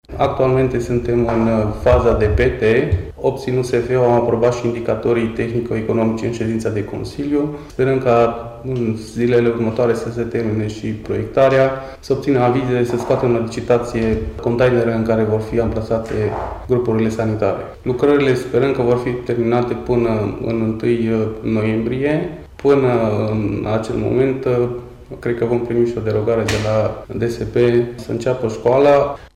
Primarul Ioan Alin Jors spune că încă din primăvară a început forarea puțurilor de apă. Ar fi vrut să construiască și grupuri sanitare dar nu au fost bani până să sosească fondurile de la Guvern: